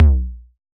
RDM_TapeA_SY1-Tom03.wav